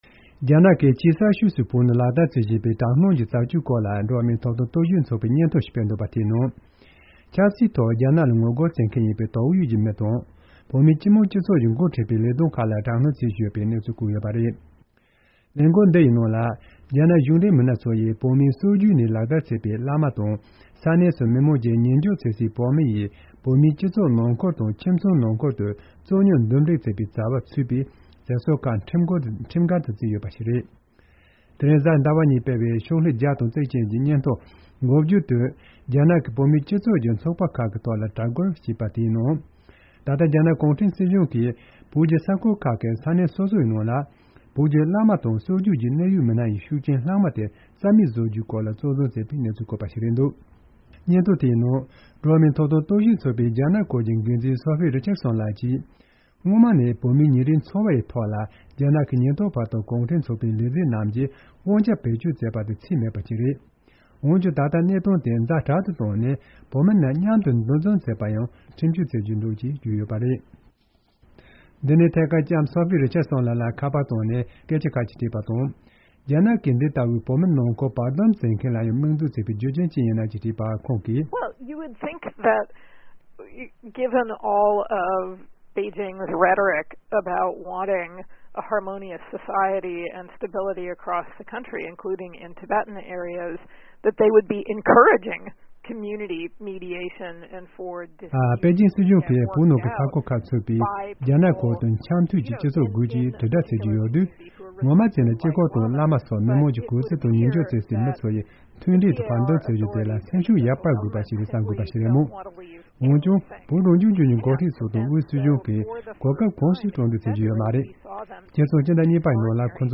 ཁ་པར་བཏང་ནས